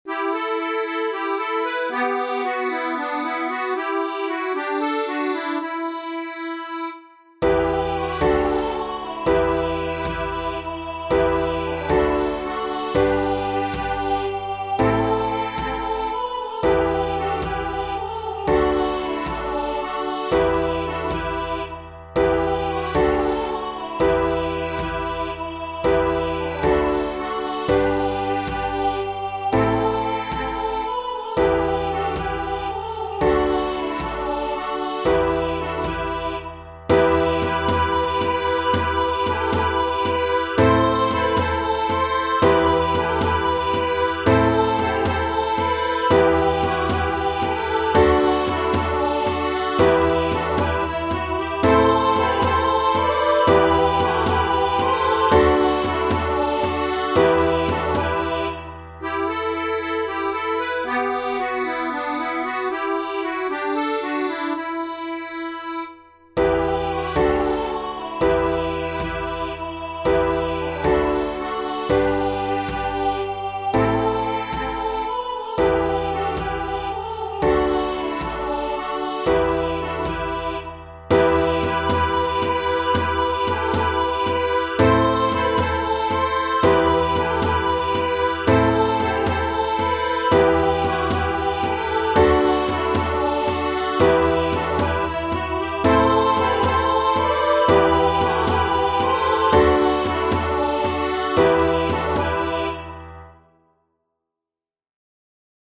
Genere: Sociali e Patriottiche